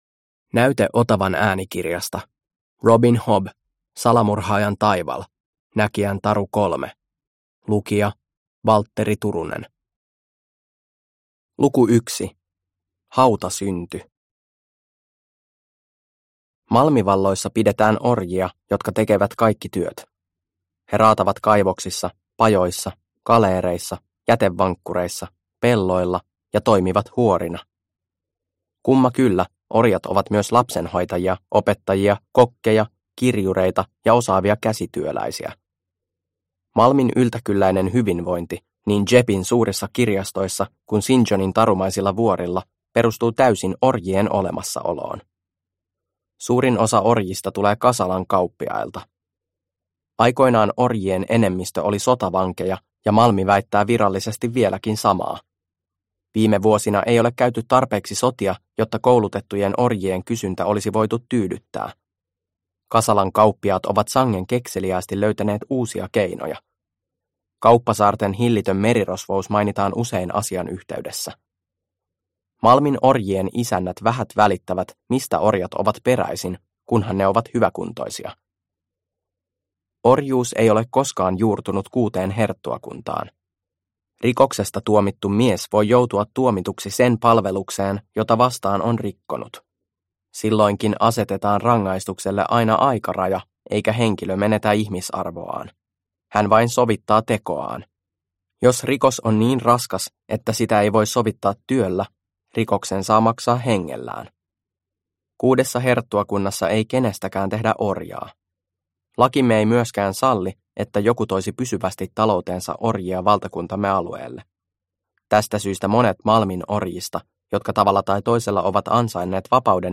Salamurhaajan taival – Ljudbok – Laddas ner